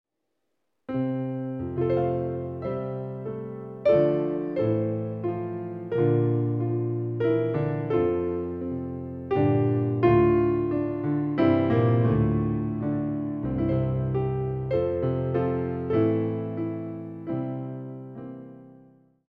solo piano CD for sale